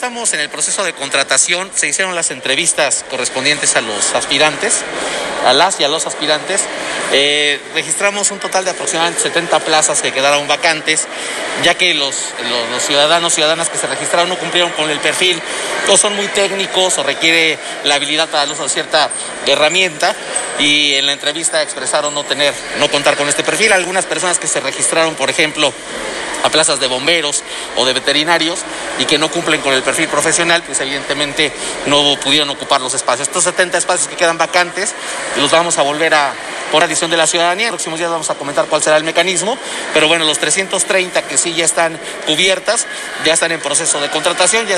En entrevista, este lunes, el funcionario municipal abundó que estos 70 espacios que están vacantes se volverán a ofertar en los próximos días a fin de que las y los ciudadanos que cubran los requisitos puedan participar; con relación a los 330 que fueron cubiertos acotó que están por integrarse en los próximos días al ayuntamiento para que a más tardar el próximo jueves ingresen en su totalidad.